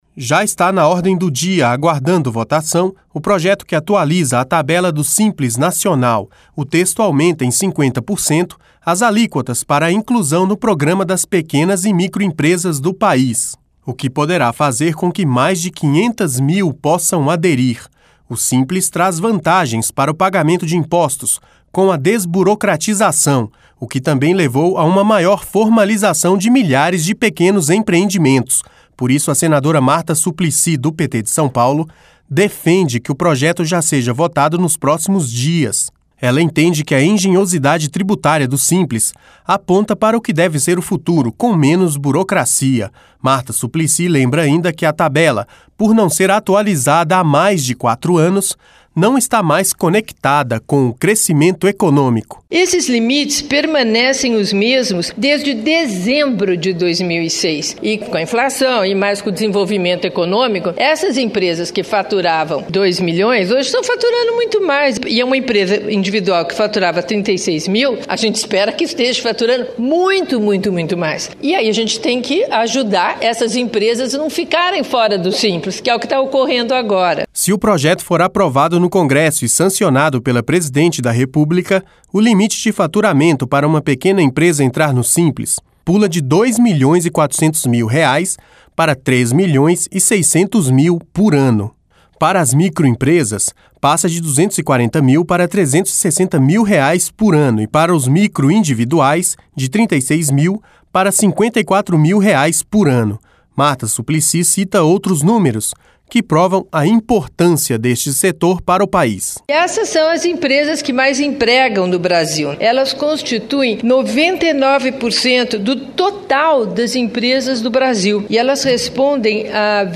(MARTA SUPLICY): Estes limites permanecem os mesmos desde dezembro de 2006, e com a inflação e mais com o desenvolvimento econômico, estas empresas que faturavam 2 milhões hoje estão faturando muito mais.